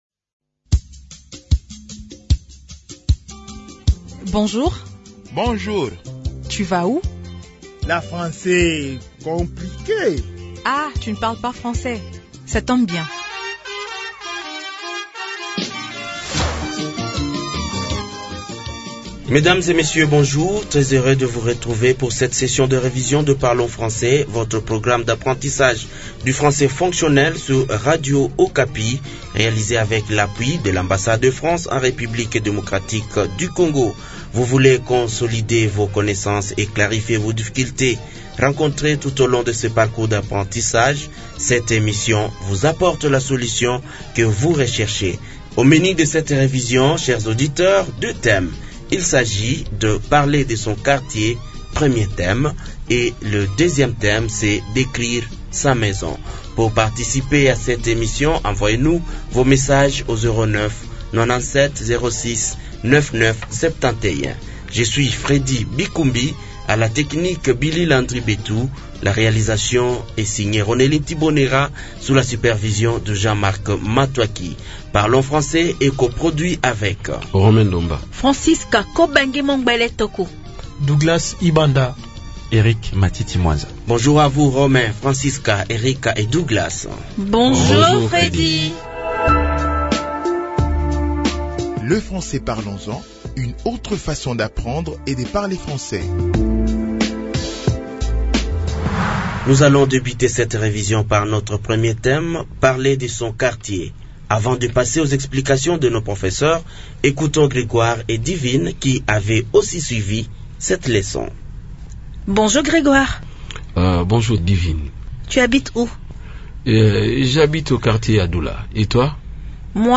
Apprenez, à travers cette leçon de révision, les expressions usuelles pour décrire votre quartier. Chers apprenants, cette leçon met à votre disposition des mots et des phrases simples pour vous aider à parler français .